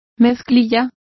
Complete with pronunciation of the translation of denims.